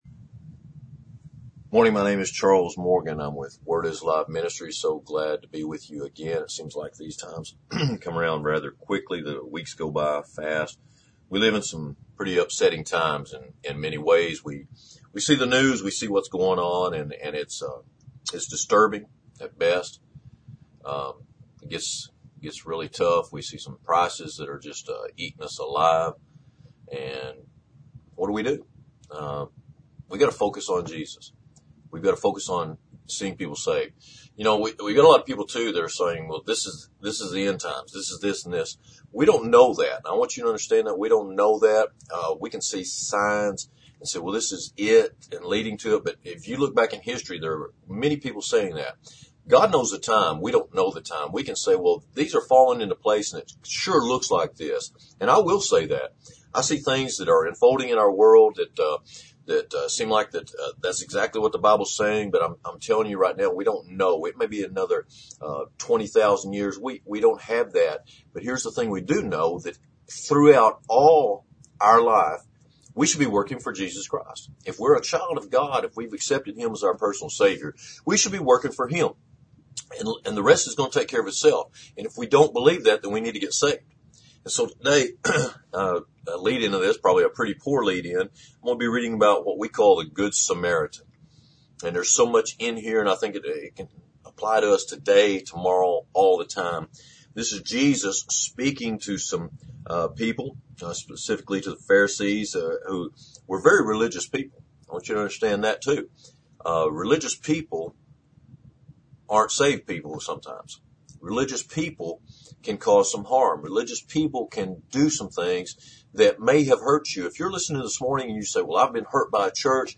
Sermon for Sunday March 13. It is all about the condition of the heart. Your actions or works will reflect what your heart condition is, including your words!